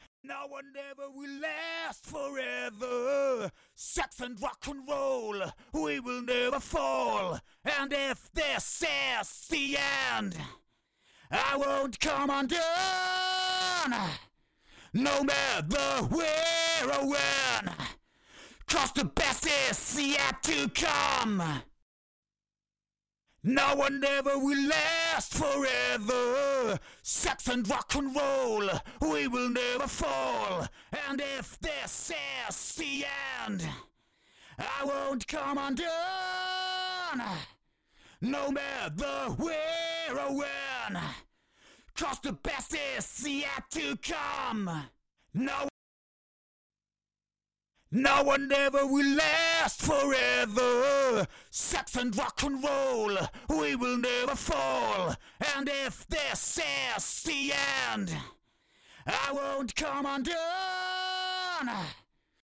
Ich gehe also davon aus, die Zerre tritt nur auf, wenn eine Pegeländerung stattfindet.
Ich hab das ganze mal aufgenommen, ensprechend dem Titel hab ich im ersten Durchgang den Input aufgedreht, im zweiten die Regelzeiten von langsam nach schnell gestellt und im dritten die Ratio erhöht.